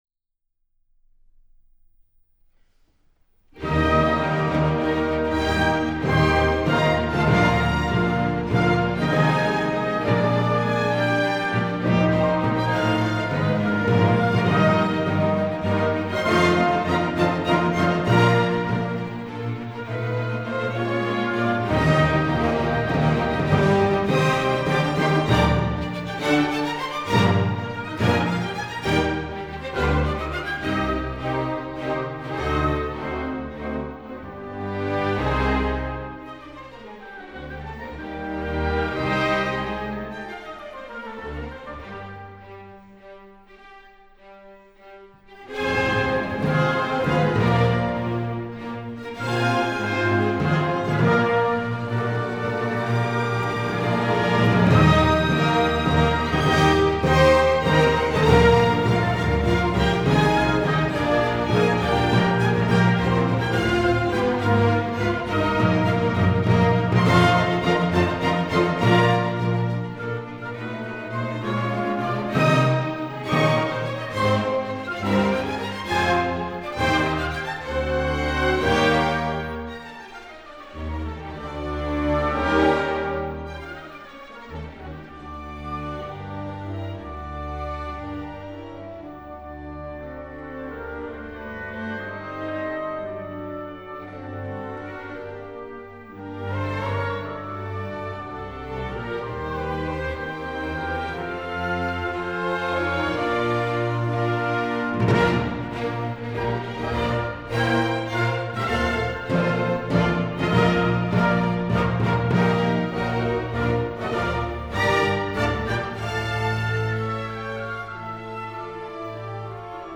分类： 古典音乐、新世纪、纯音雅乐